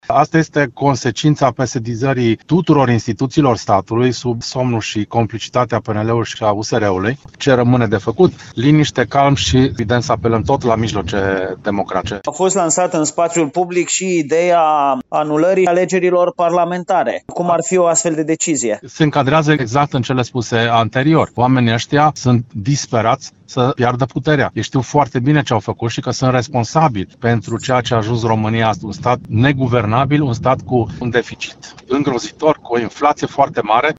Decizia Curții Constituționale de anulare a alegerilor prezidențiale și reorganizarea lor este o adevărată lovitură de stat, susține Florin Popovici, ales deputat de Timiș, pe listele AUR.